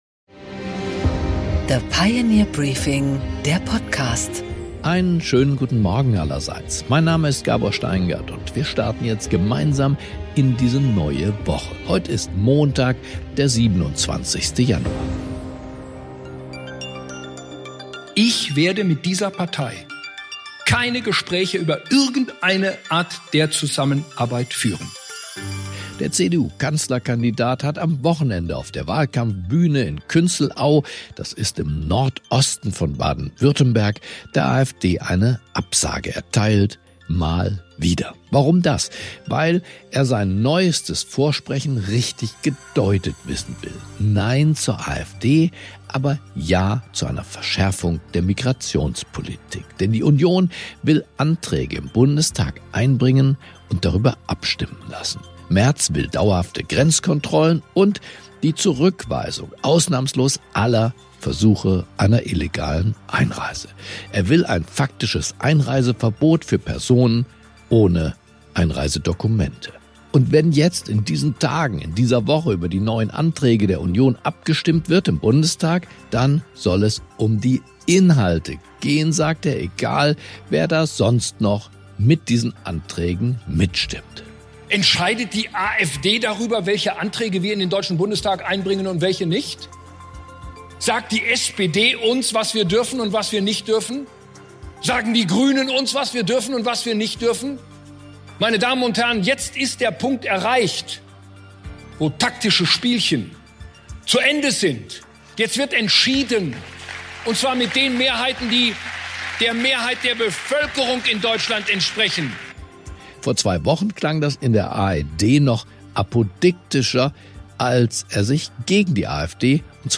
Gabor Steingart präsentiert das Pioneer Briefing
Interview